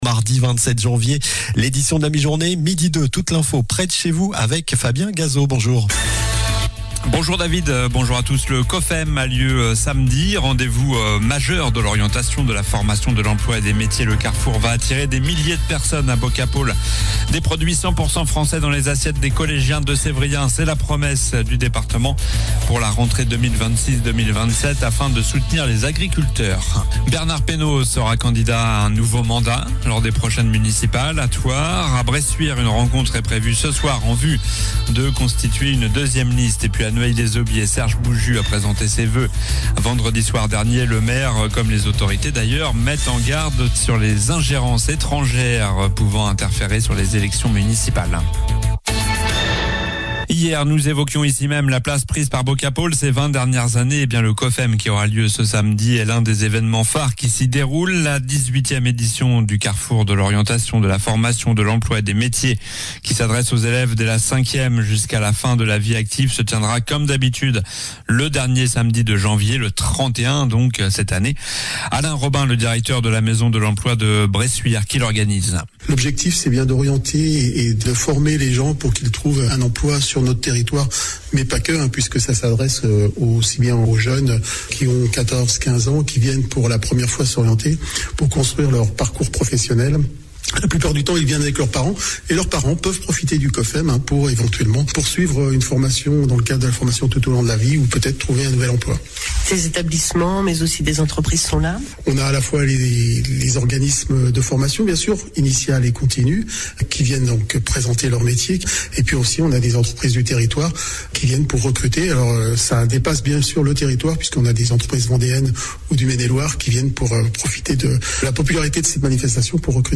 Journal du mardi 27 janvier (midi)